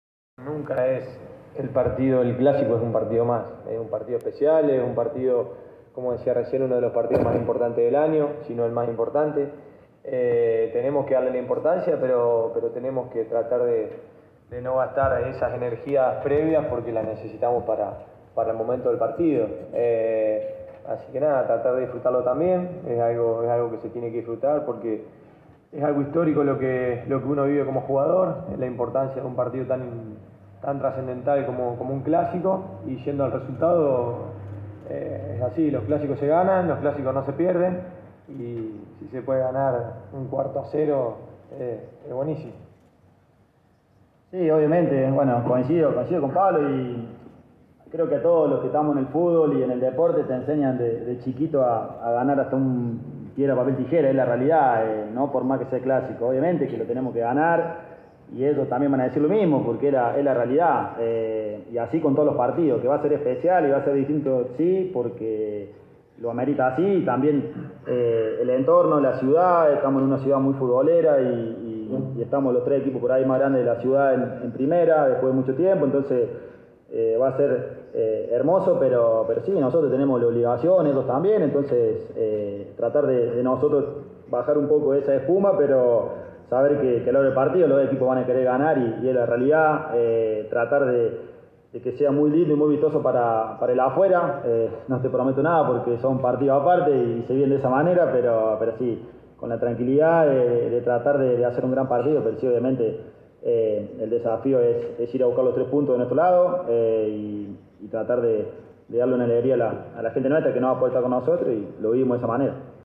Los capitanes de ambas escuadras le pusieron el primer capítulo al gran duelo de "La Docta" con una conferencia organizada por la LPF.